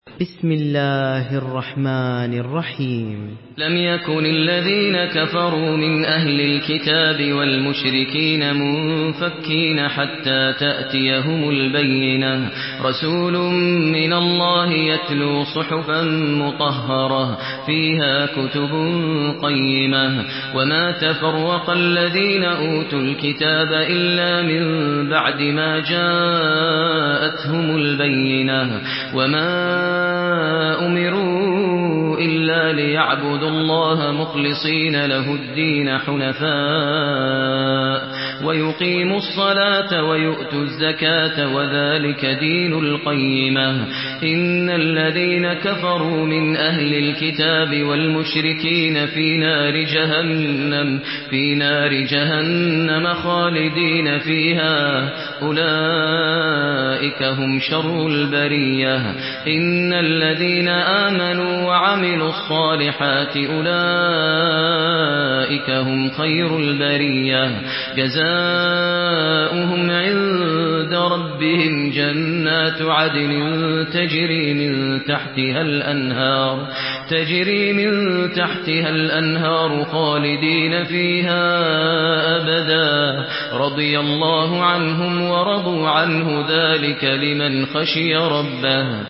Surah البينه MP3 in the Voice of ماهر المعيقلي in حفص Narration
مرتل حفص عن عاصم